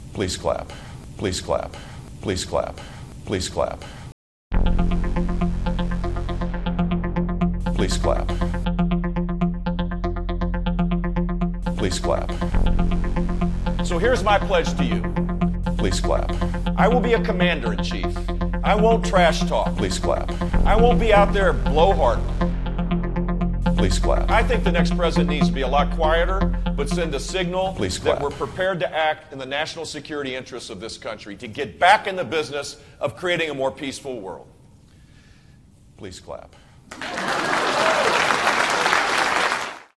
As promised, the JEB Bush “Please Clap” ringtone.